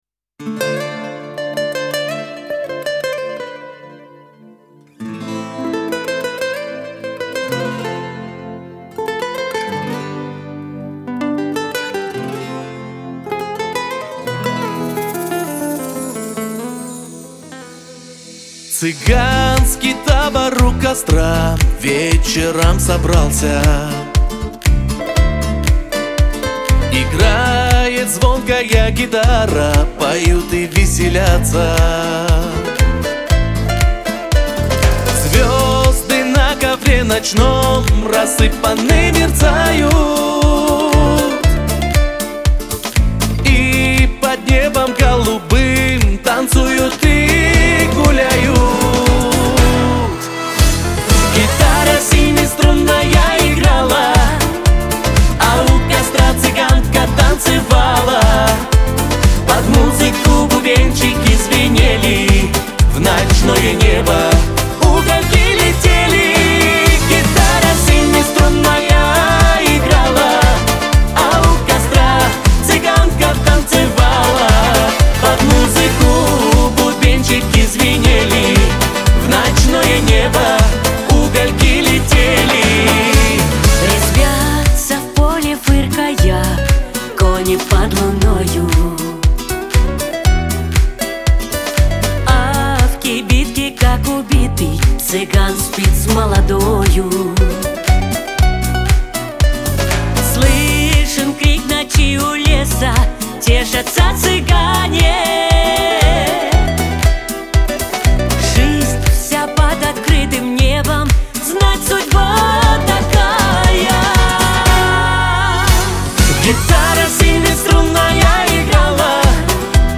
Шансон
Лирика